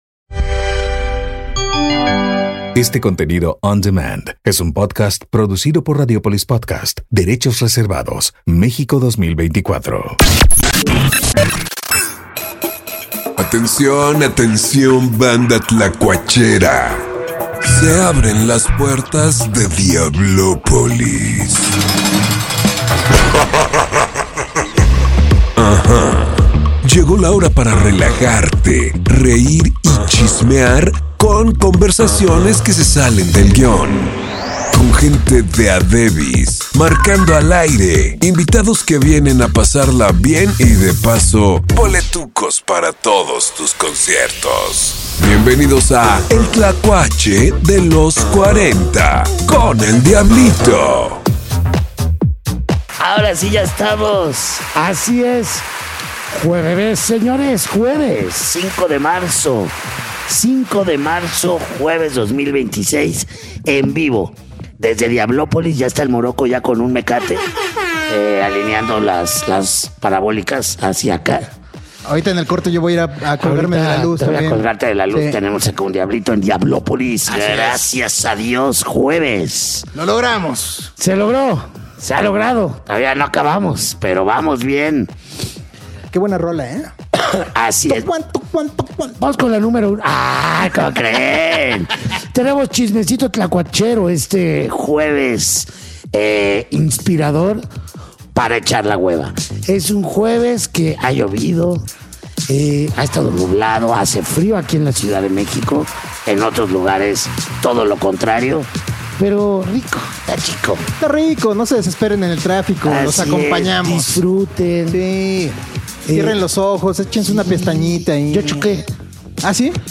Tocando en vivo